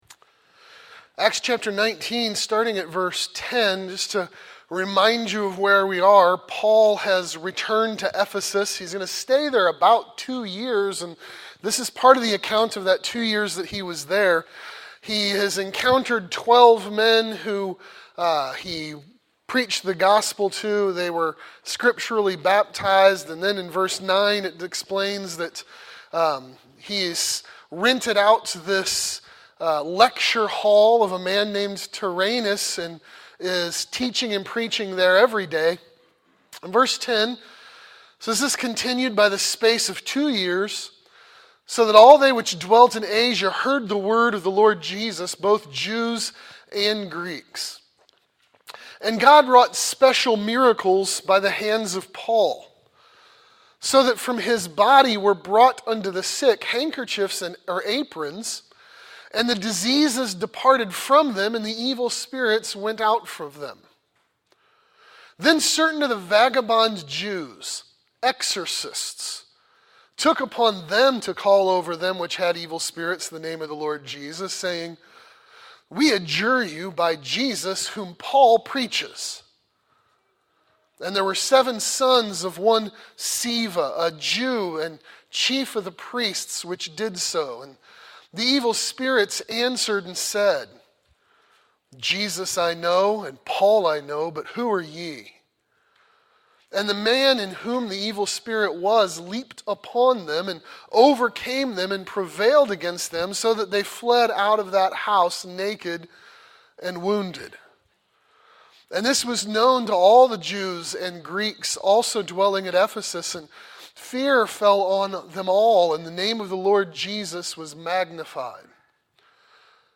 Victory for the Gospel | SermonAudio Broadcaster is Live View the Live Stream Share this sermon Disabled by adblocker Copy URL Copied!